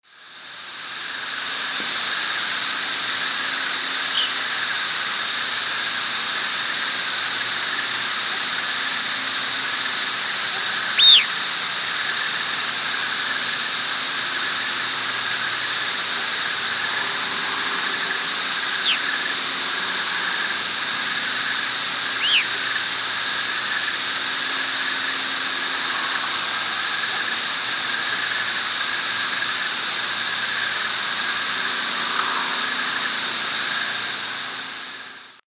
presumed Gray-cheeked Thrush nocturnal flight calls
Nocturnal flight call sequences: